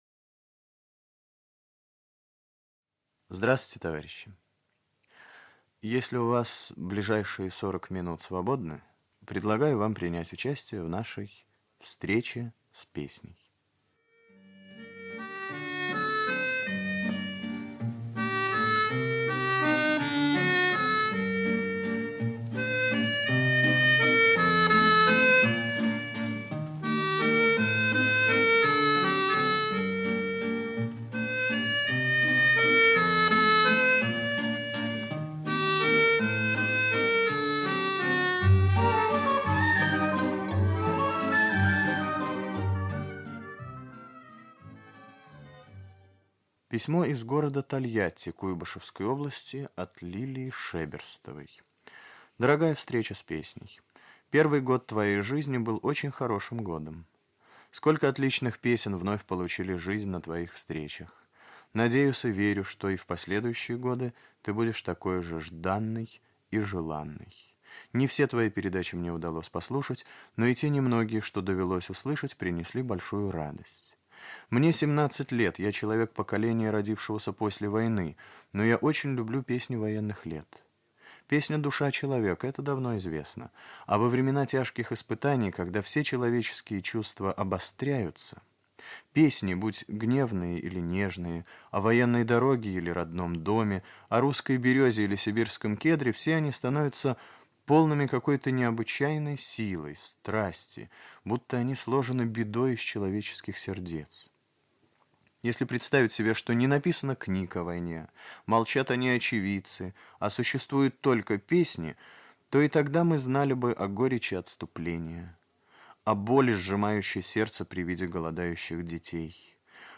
Русская народная песня
в сопровождении гитары
1968 Ведущий - автор Татарский Виктор.